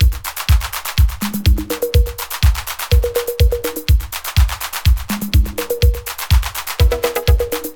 • 124 Bpm 00s Disco Drum Loop C# Key.wav
Free drum loop - kick tuned to the C# note. Loudest frequency: 1719Hz
124-bpm-00s-disco-drum-loop-c-sharp-key-CbD.wav